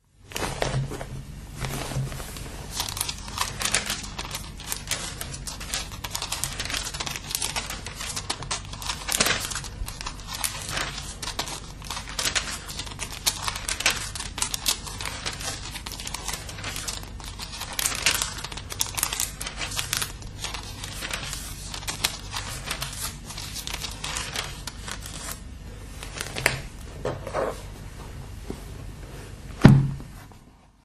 描述：翻开教会在1942年送给我父亲的《圣经》（荷兰语译本）中的《历代志》一书的书页。